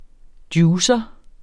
Udtale [ ˈdjuːsʌ ]